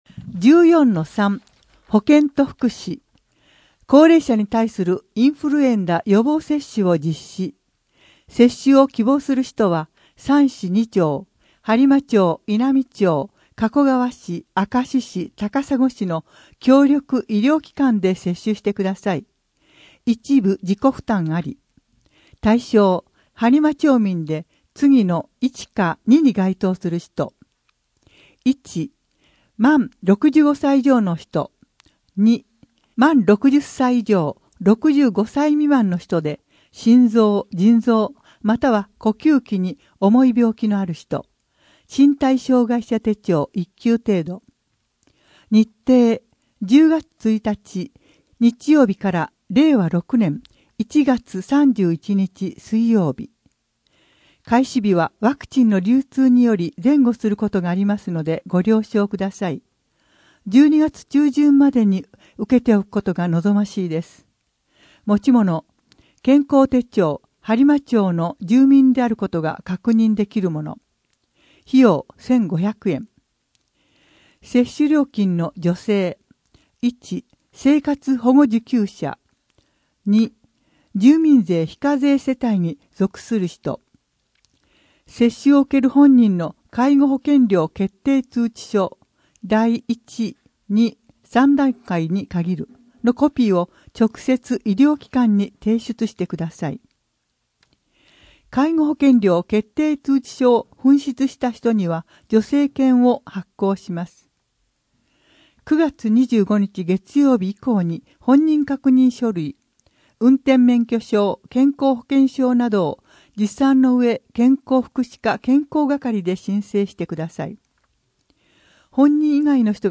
声の「広報はりま」10月号
声の「広報はりま」はボランティアグループ「のぎく」のご協力により作成されています。